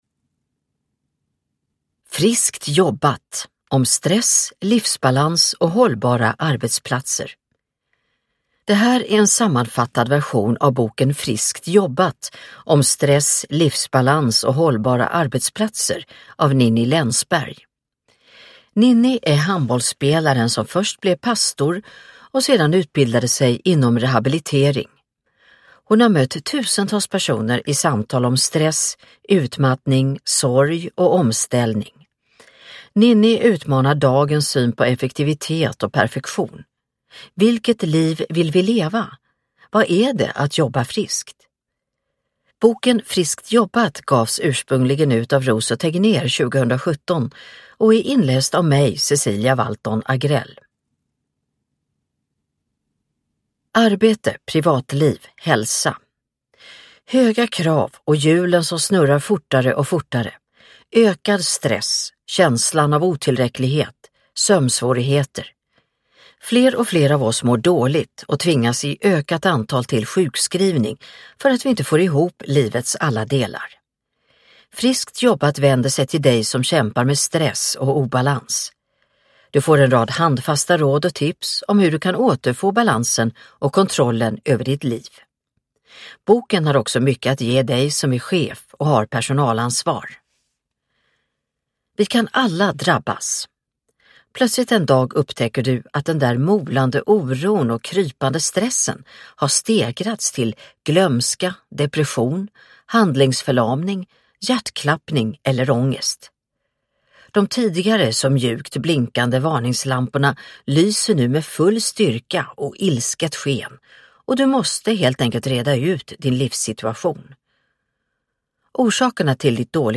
Friskt jobbat – Om stress, livsbalans och hållbara arbetsplatser – Ljudbok – Laddas ner